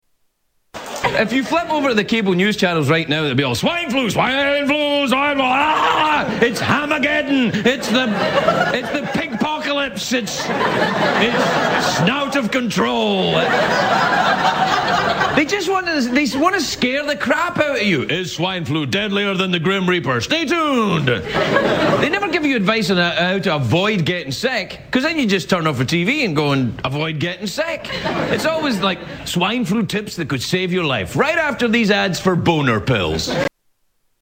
Craig Ferguson jokes about Swine Flu